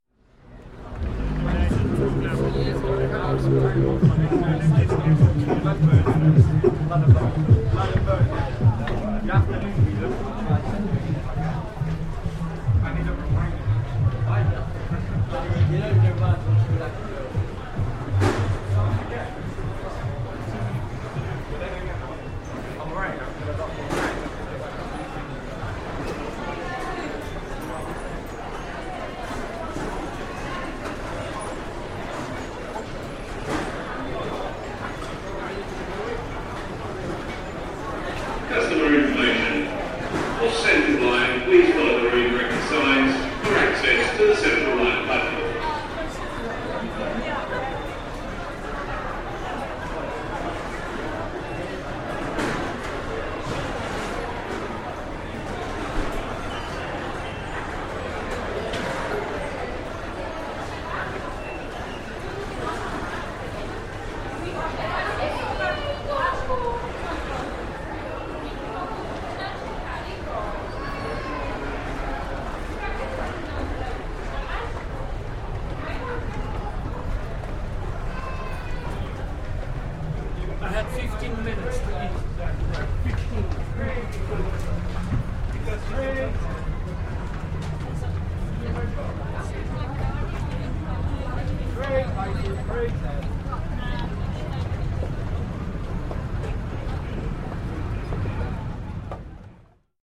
Oxford Circus station underpass
Field recording from the London Underground by London Sound Survey.